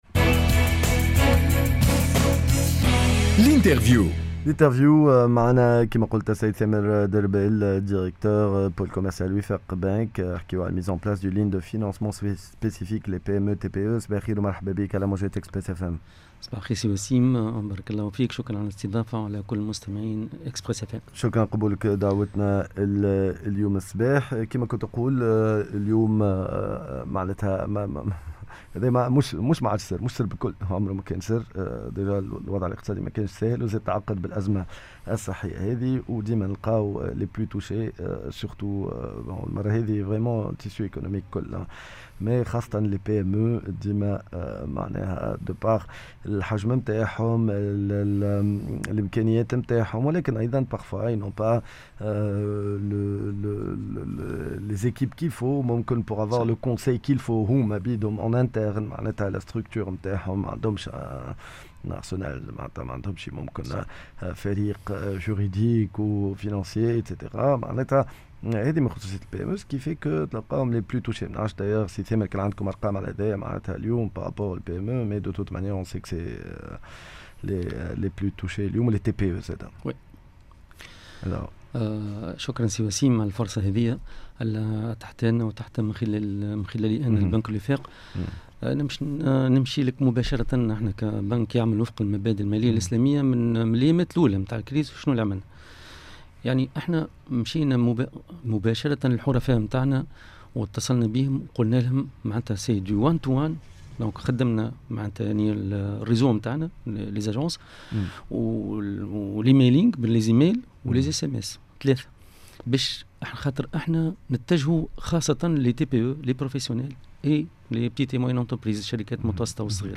EcoMag L'interview